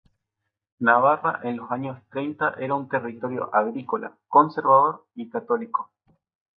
con‧ser‧va‧dor
/konseɾbaˈdoɾ/